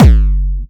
VEC3 Bassdrums Dirty 07.wav